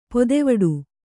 ♪ podevaḍu